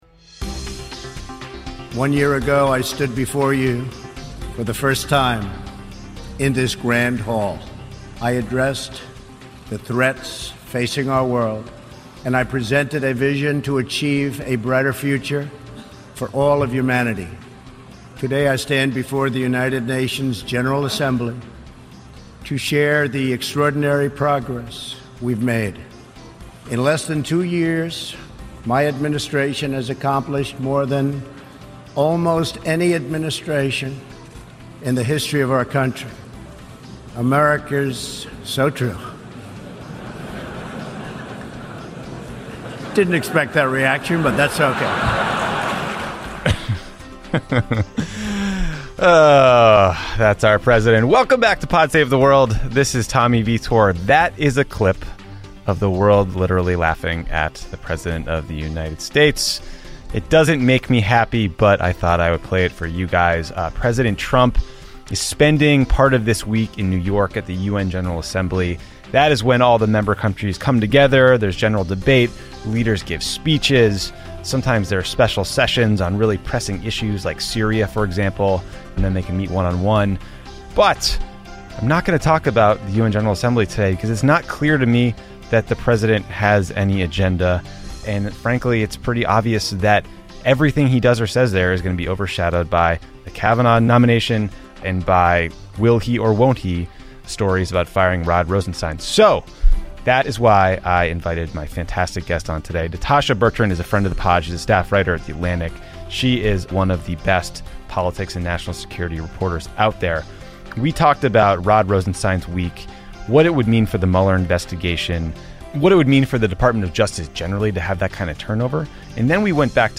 Tommy talks with The Atlantic's Natasha Bertrand about how the potential departure of Deputy AG Rod Rosenstein would impact the Mueller investigation and operations at DOJ. Then they discuss what we've learned about Russia's efforts to influence our elections over the past two years, and the shady right-wing characters that link Trump to Wikileaks.